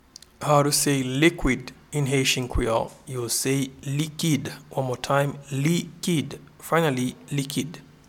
Pronunciation and Transcript:
Liquid-in-Haitian-Creole-Likid.mp3